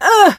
rosa_hit_06.ogg